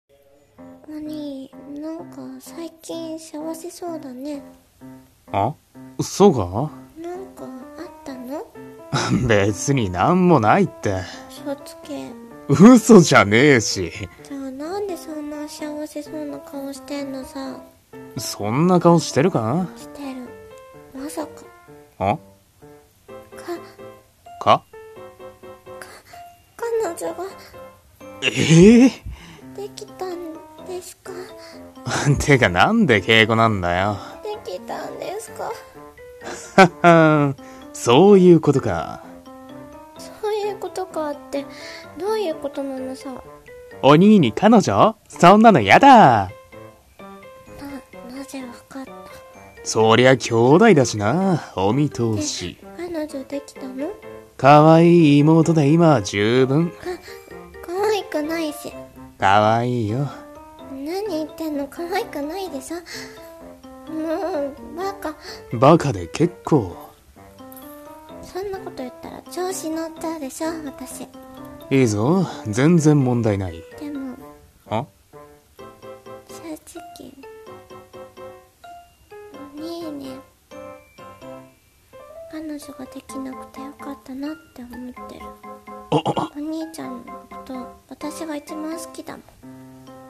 【声劇】まさか…お兄に彼女？？